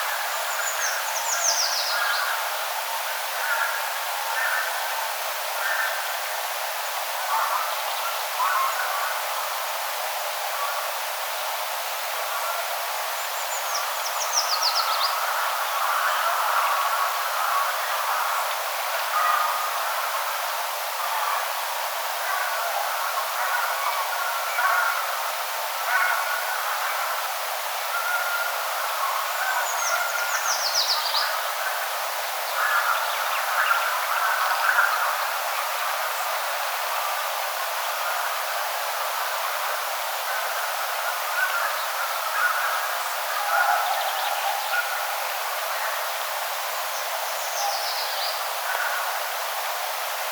puukiipijän laulua
puukiipijan_laulua.mp3